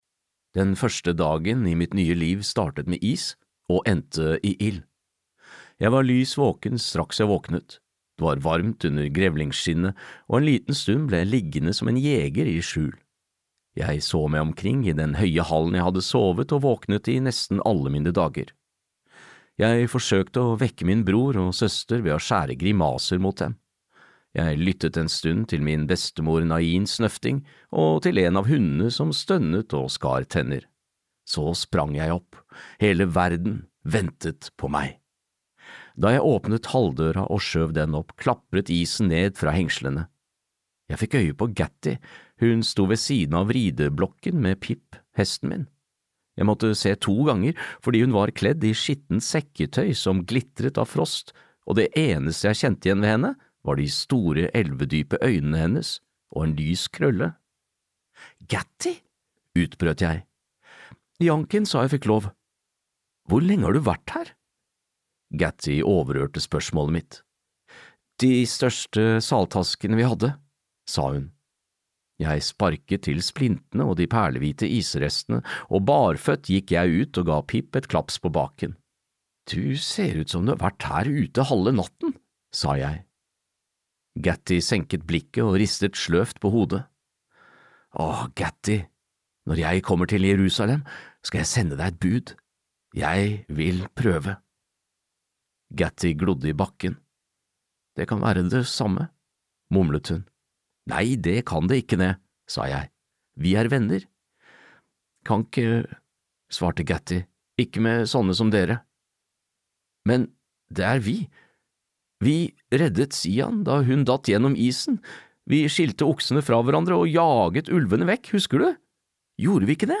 Arthur - ved korsveiene (lydbok) av Kevin Crossley-Holland